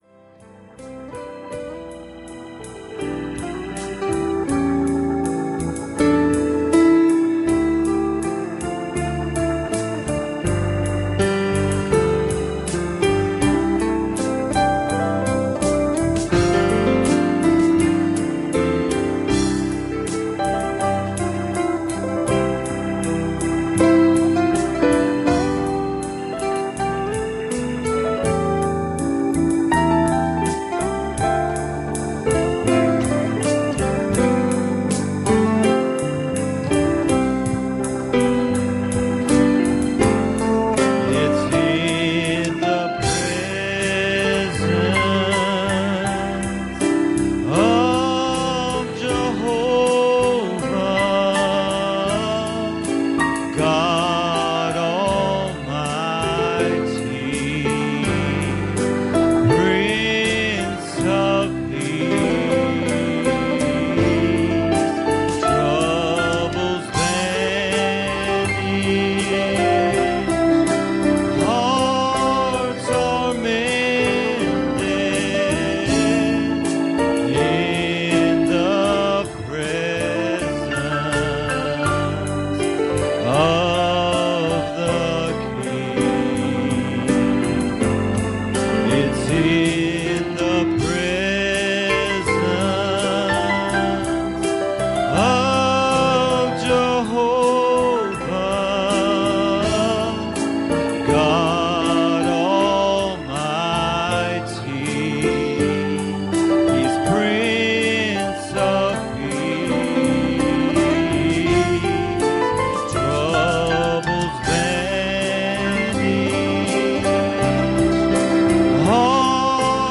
Service Type: Special Service